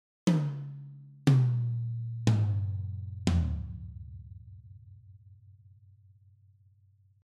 It sounds like natural close micing of good sounding toms to me.
In fact that sounds exactly like a tom without any processing whatsoever to me.
Attachments SSD TOMS.mp3 SSD TOMS.mp3 283.4 KB · Views: 106